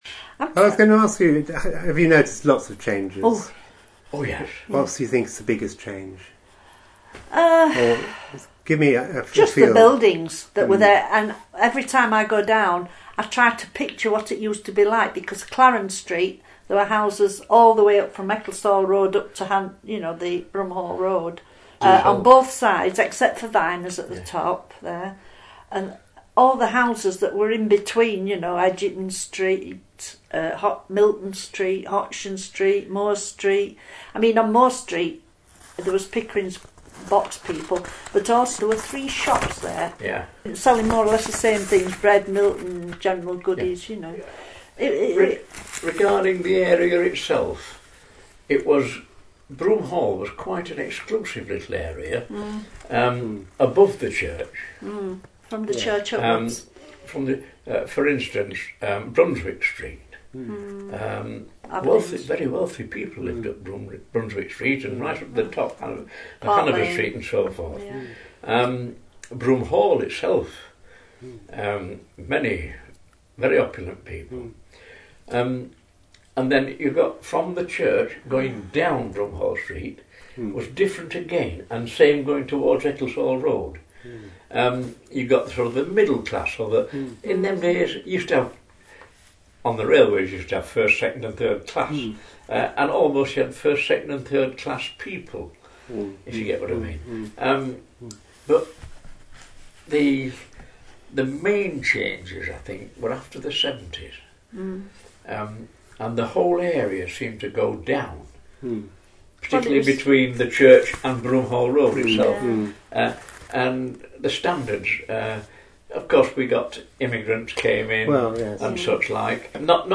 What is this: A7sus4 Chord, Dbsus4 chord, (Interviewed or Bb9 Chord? (Interviewed